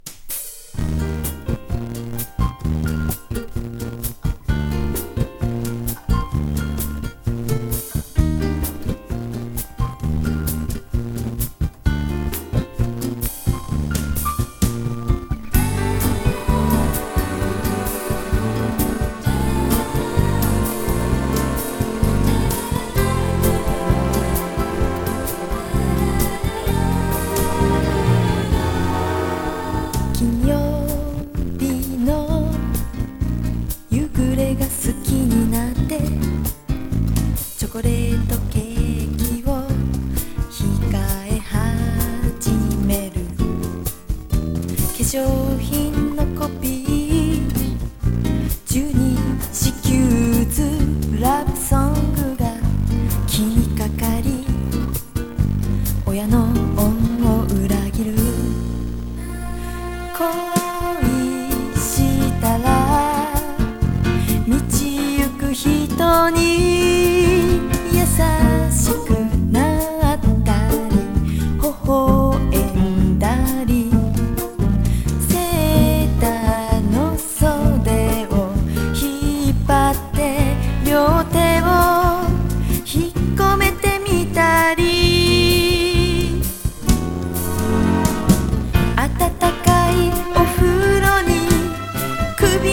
ボッサをとても自然に取り入れた
80's ロック / ポップス